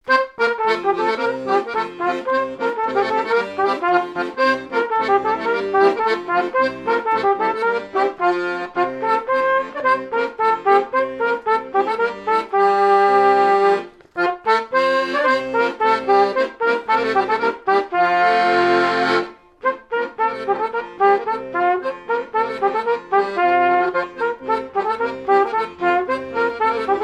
quadrille
airs de danses issus de groupes folkloriques locaux
Pièce musicale inédite